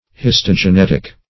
Search Result for " histogenetic" : The Collaborative International Dictionary of English v.0.48: Histogenetic \His`to*ge*net"ic\, a. [See Histogeny .]